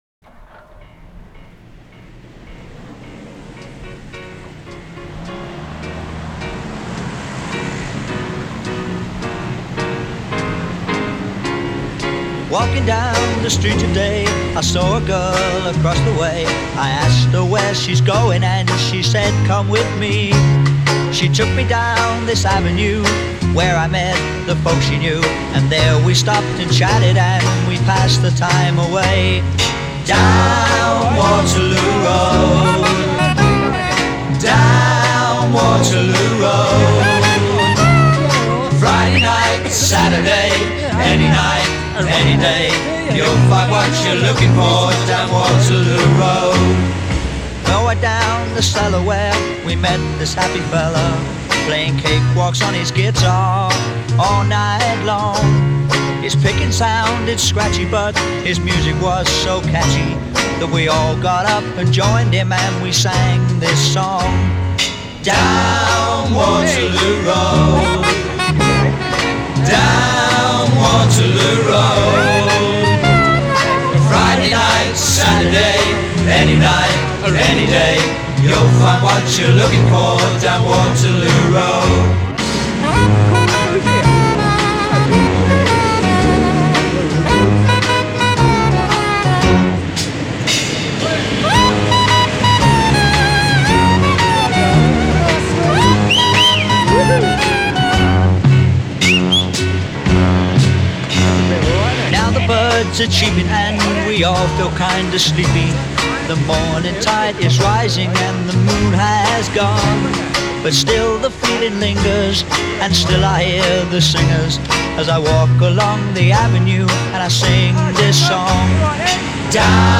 Похожи не по тембру голоса,а по стилистике.
Кент на основе психоделической поп-группы